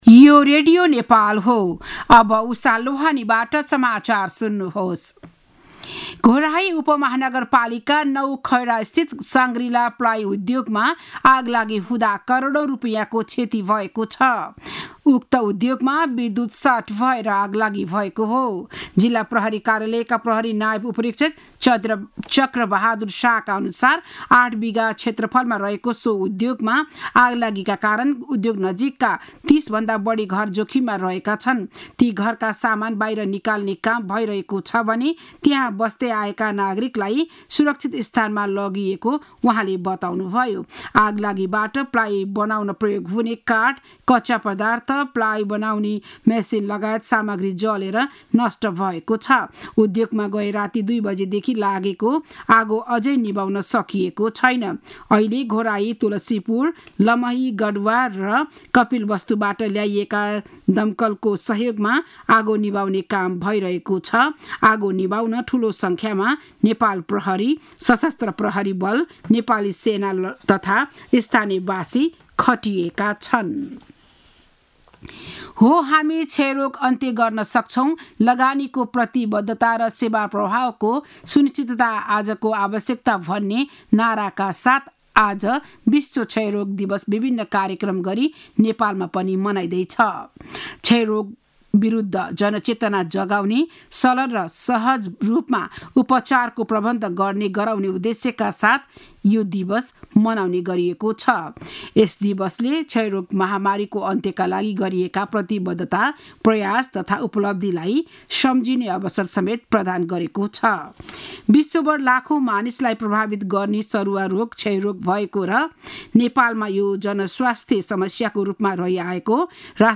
बिहान ११ बजेको नेपाली समाचार : ११ चैत , २०८१